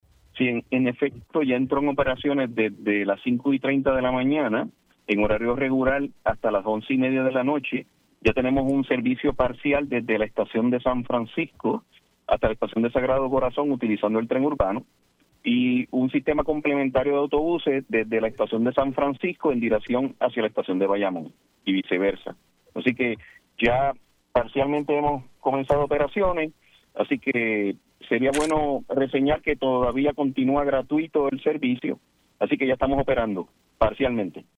El director ejecutivo de la Autoridad de Transporte Integrado (ATI), Josué Menéndez reconoció en Pega’os en la Mañana que una fluctuación de voltaje pudo haber sido la causa de la explosión en la subestación del Tren Urbano, ocurrida el pasado miércoles, 14 de mayo.